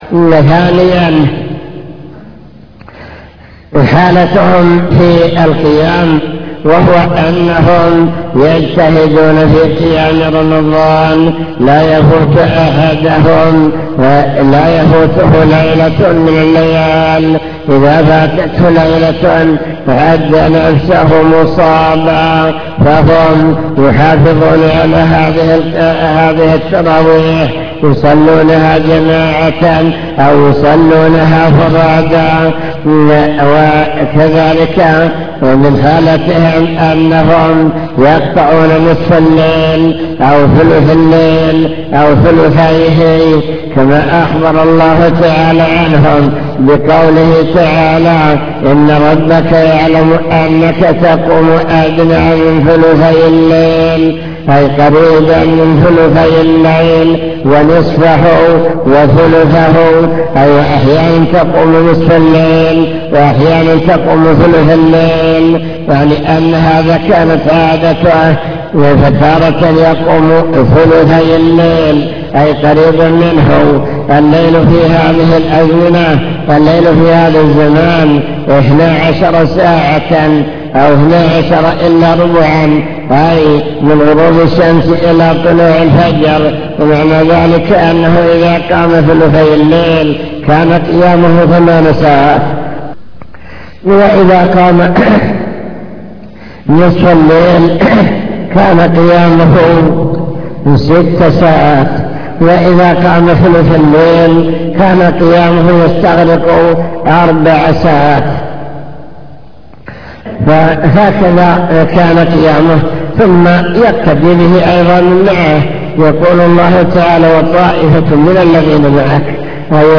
المكتبة الصوتية  تسجيلات - محاضرات ودروس  مجموعة محاضرات ودروس عن رمضان هدي السلف الصالح في رمضان